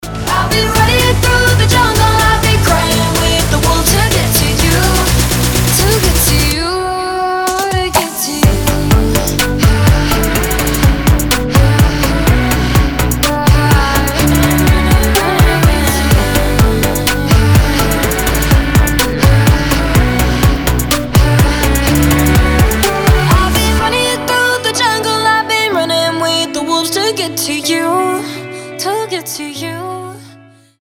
• Качество: 320, Stereo
женский вокал
dance
Electronic
EDM
чувственные